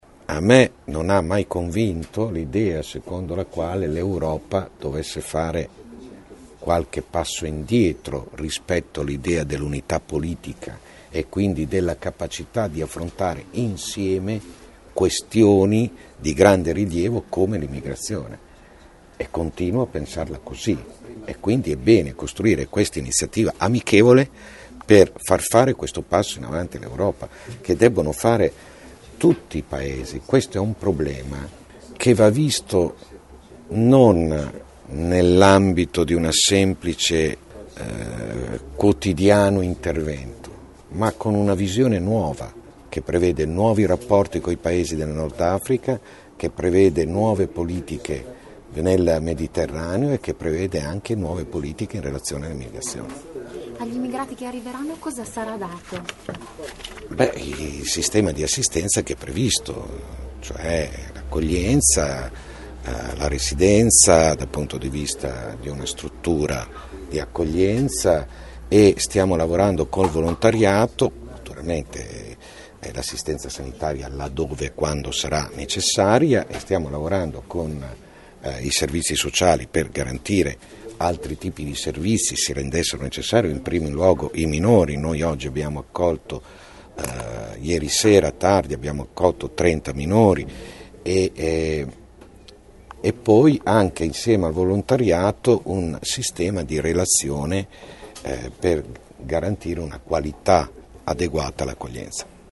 Ascolta Vasco Errani